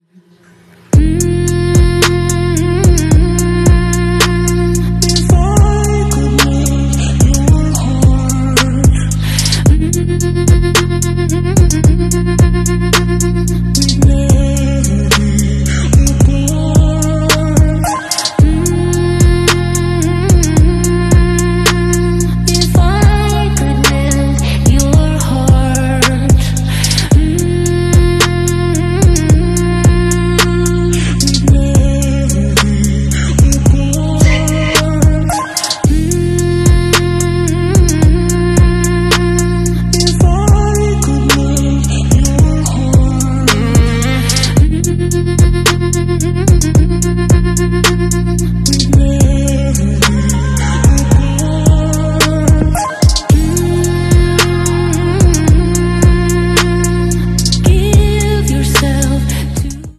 "Udarata Menike" Express Train passing sound effects free download
Great Western Mountain Range